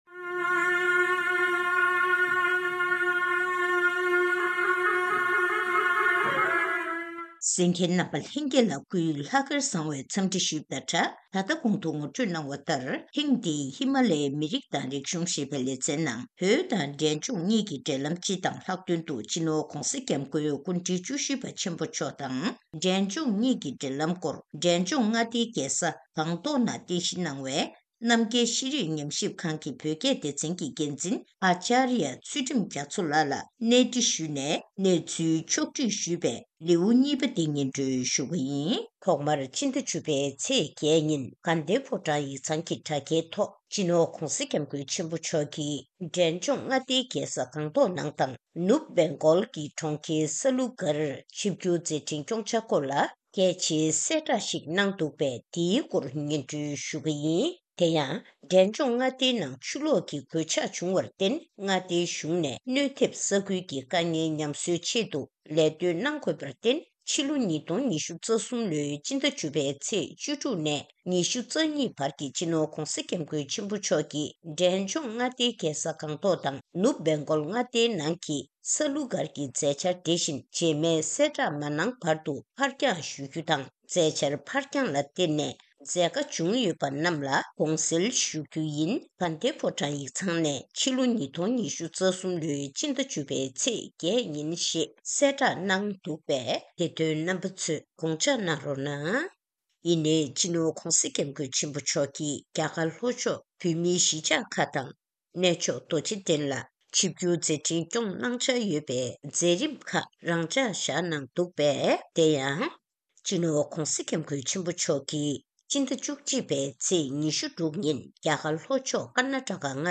གནས་འདྲི་ཞུས་ནས་གནས་ཚུལ་ཕྱོགས་བསྒྲིགས་ཞུས་པ་དེ་གསན་རོགས་གནང་།